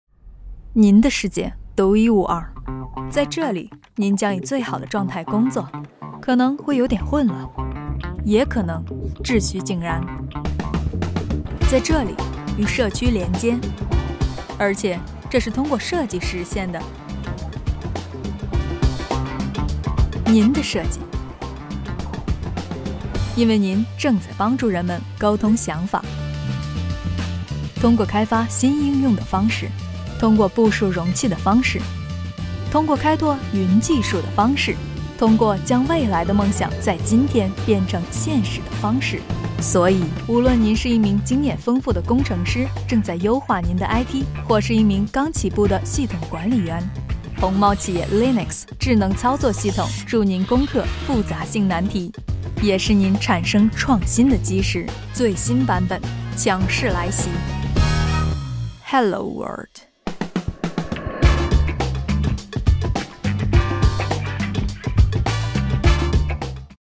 Female
Chinese (Mandarin)
Yng Adult (18-29), Adult (30-50)
Urban